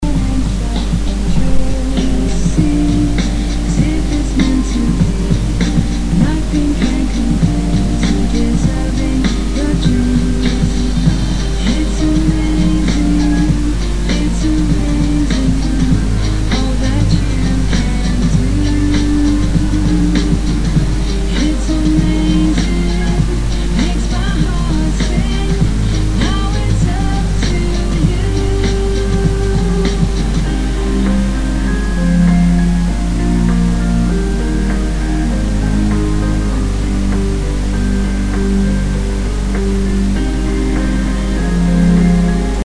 Будьте добры, помогите определить песню и исполнителя по этому некачественному фрагменту.